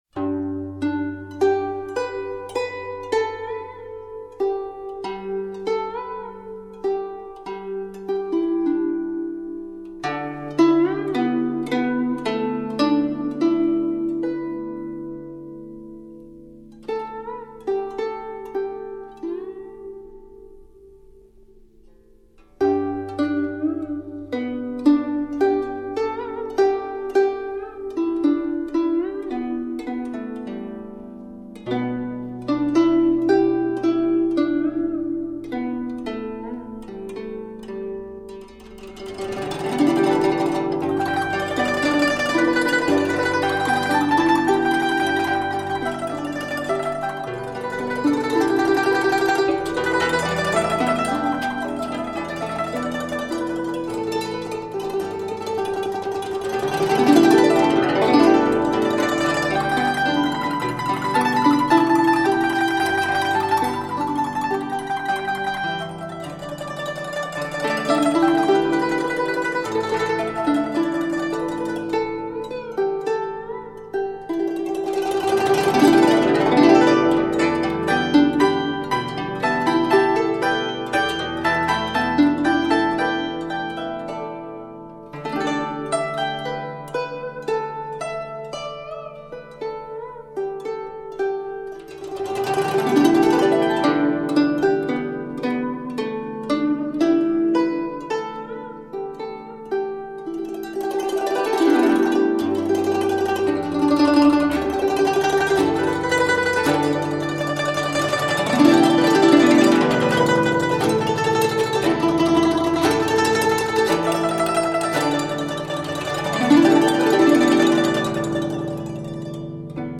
古筝演奏